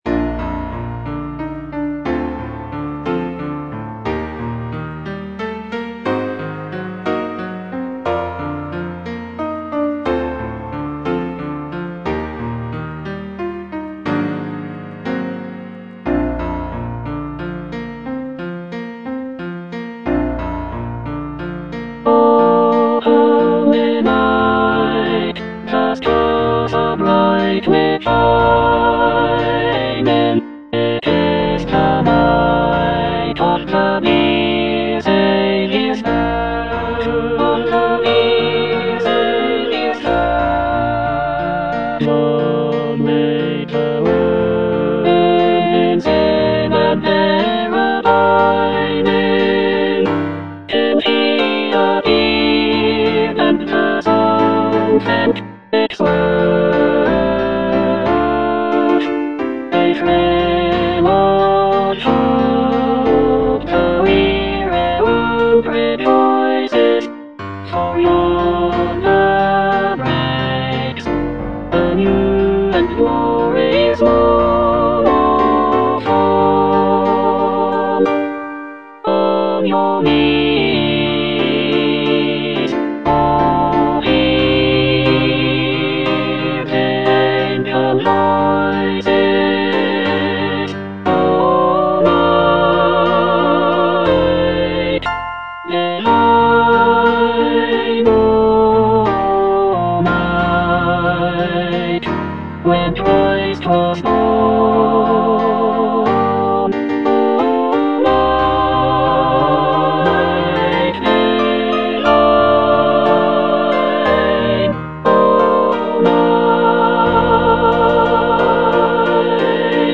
All voices